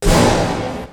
OS Project Slam.wav